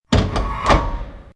CHQ_FACT_switch_popup.ogg